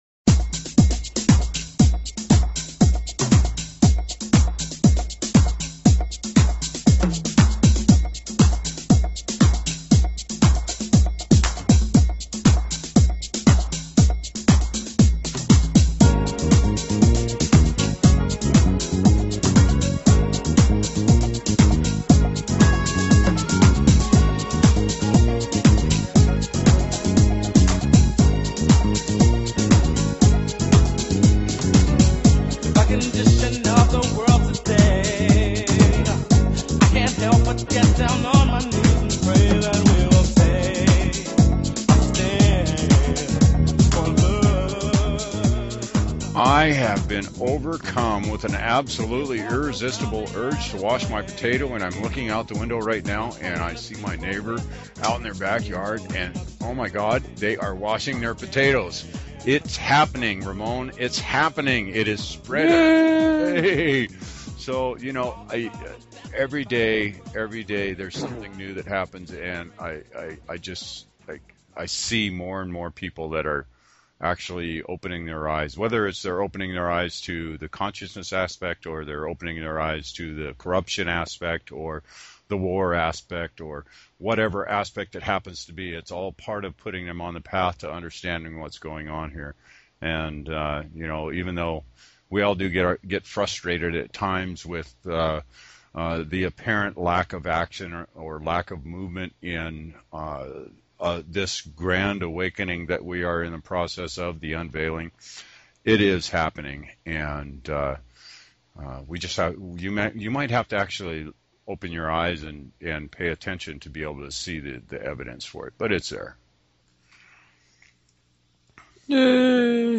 Talk Show Episode, Audio Podcast, The_Hundredth_Monkey_Radio and Courtesy of BBS Radio on , show guests , about , categorized as